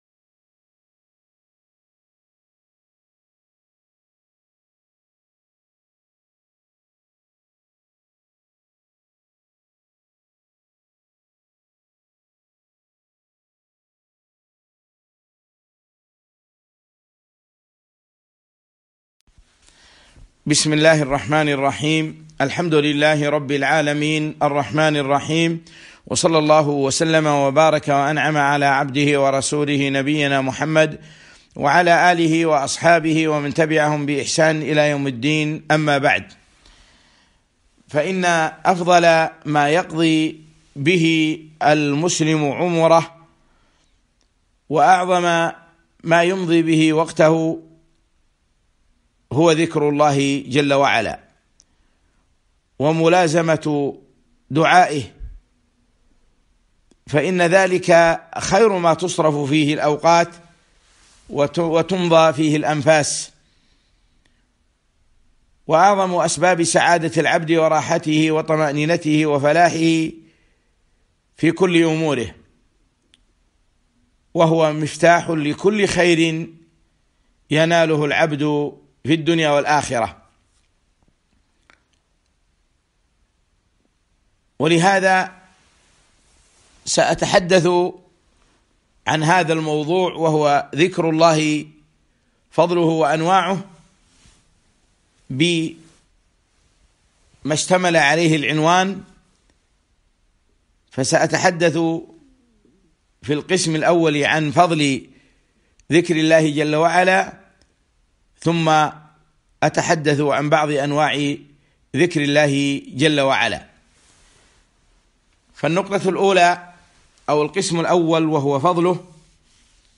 محاضرة - ذكر الله فضله وأنواعه